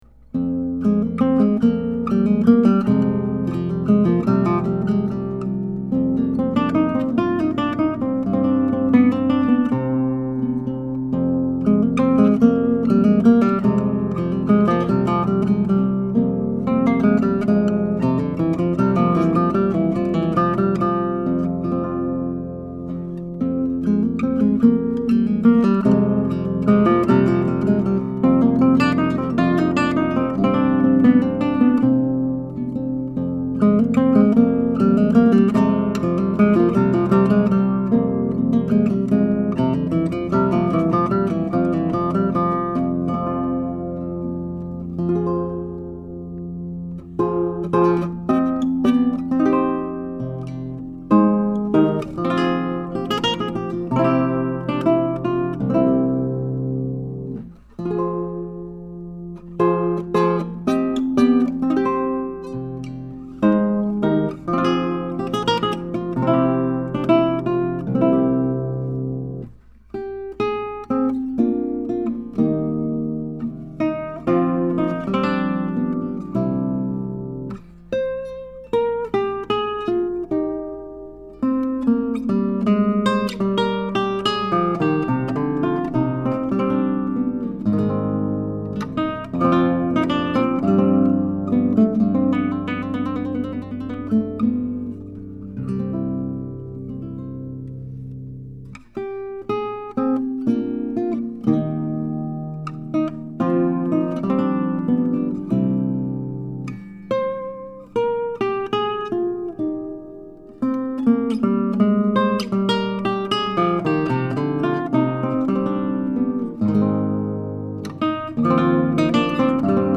11-String Guitar
This guitar has a AAA-grade solid Cedar top, laminated East Indian Rosewood back & Sides, ebony fretboard, and creates a beautifully-balanced sound with nice trebles, deep basses, and excellent resonance and sympathetic sustain.
I have the guitar tuned in Romantic tuning in to G, a standard 11-string tuning where 1-6 are tuned up a minor third, and 7-11 descend step-wise: 7=D, 8=C, 9=B, 10 =A, 11=G. It can also be tuned in Dm tuning for Baroque Dm lute music.
Here are 12 quick, 1-take MP3s of this guitar, tracked using a vintage Neumann U87 mic, into a TAB-Funkenwerk V78M tube preamp using a Sony PCM D1 flash recorder. This is straight, pure signal with no additional reverb, EQ or any other effects.
Spanish Renaissance)